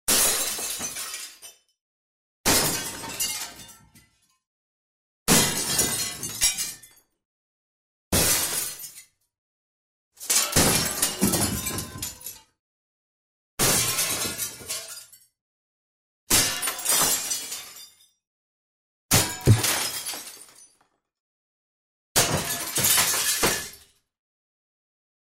Звуки трескающегося стекла
На этой странице собраны звуки трескающегося и разбивающегося стекла разных типов: от хрустальных бокалов до автомобильных стёкол.